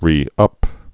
(rē-ŭp)